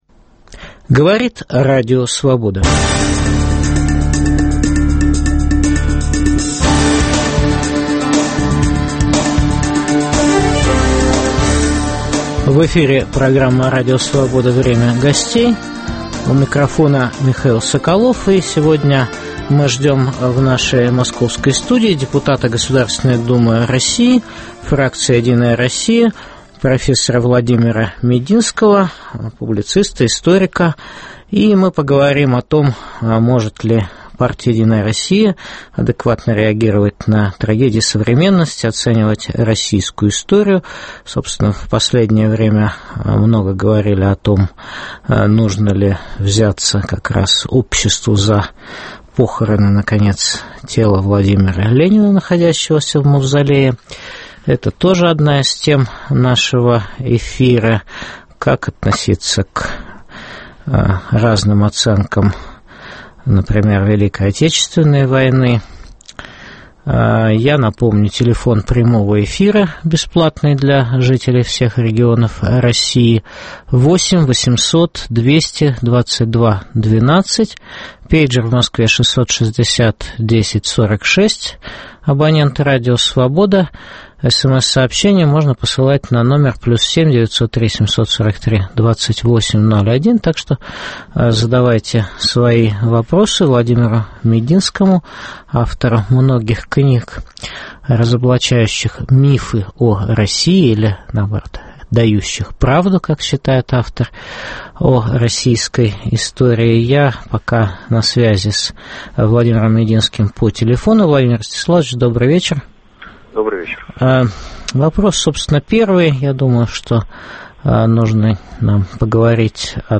Может ли партия "Единая Россия" адекватно реагировать на трагедии современности и оценивать российскую историю? В студии – инициатор кампании за похороны тела Ленина, депутат Государственной Думы России (фракция "Единая Россия"), профессор Владимир Мединский.